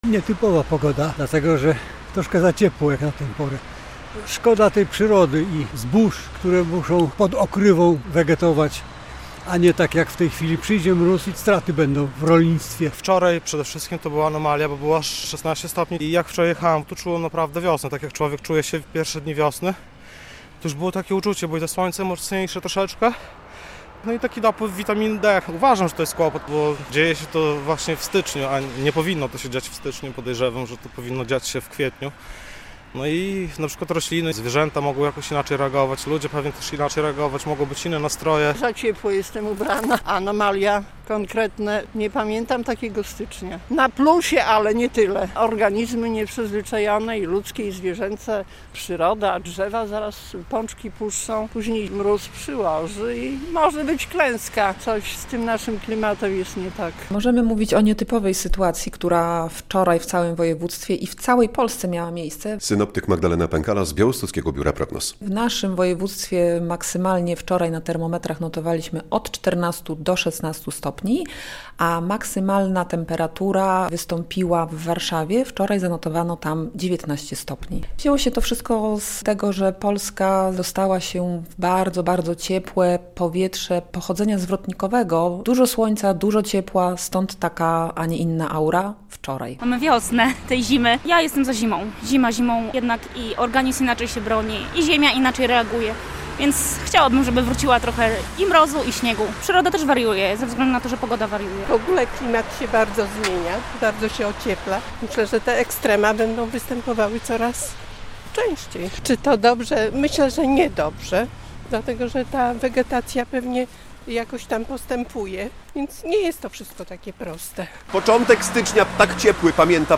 Wiosna w styczniu - relacja